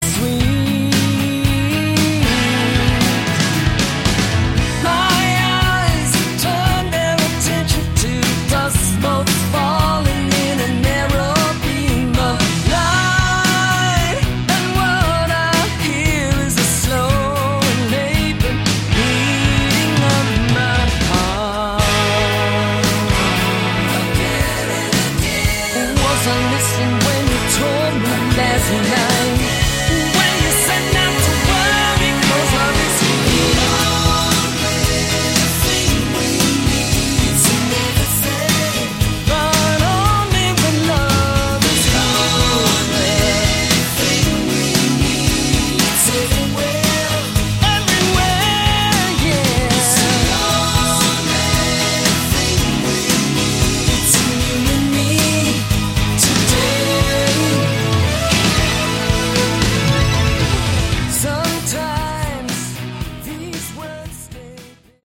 Category: AOR / Melodic Rock
guitar, keyboards, vocals
keyboards, percussion, vocals
bass, vocals
drums